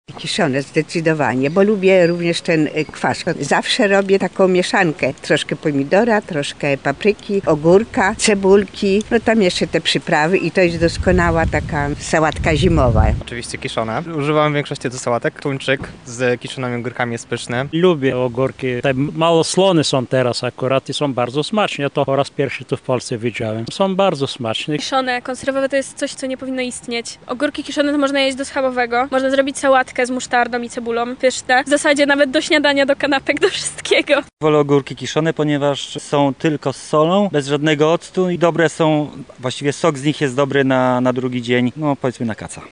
Zapytaliśmy mieszkańców Lublina, czy preferują te kiszone, czy jednak chętniej sięgają po konserwowe:
sonda